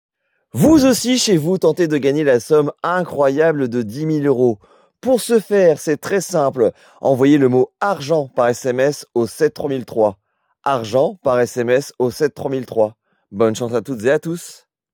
AUDIOTEL VOIX OFF
- Baryton